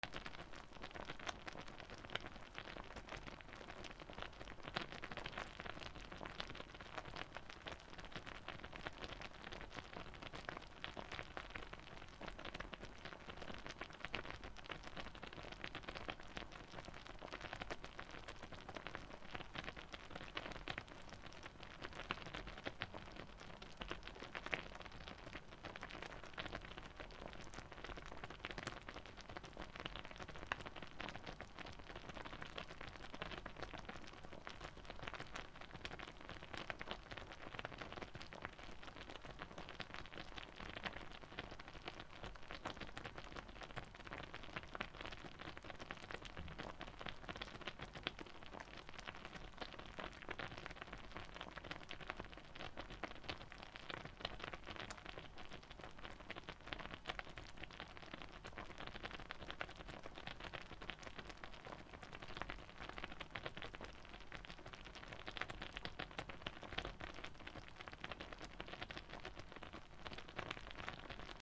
鍋 グツグツ(弱火)